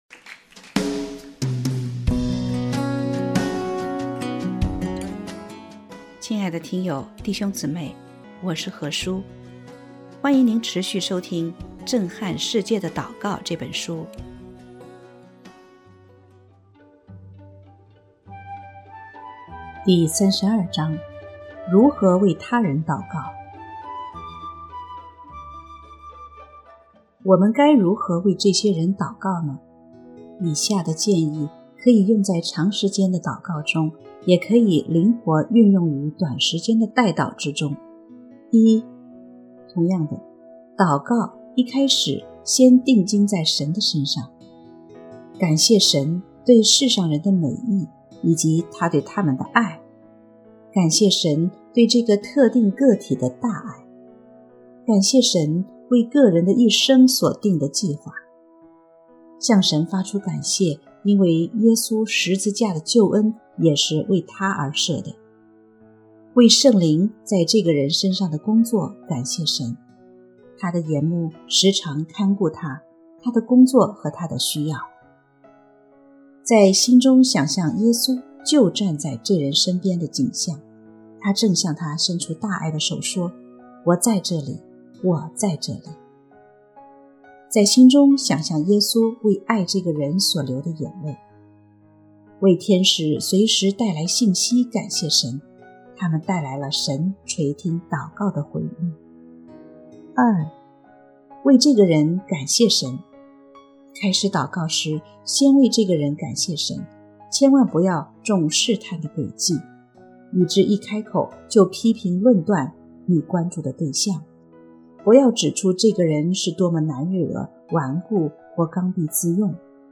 首页 > 有声书 | 灵性生活 | 震撼世界的祷告 > 震撼世界的祷告 第三十二章：如何为他人祷告